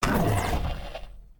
hurt1.ogg